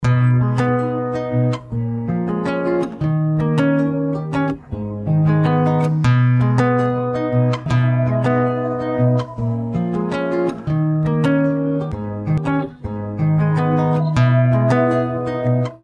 Ищу библиотеку или VSTi акустической гитары с пьезодатчиком (пример MP3)
Ищу библиотеку или VSTi акустической гитары с пьезодатчиком (электро-подключением).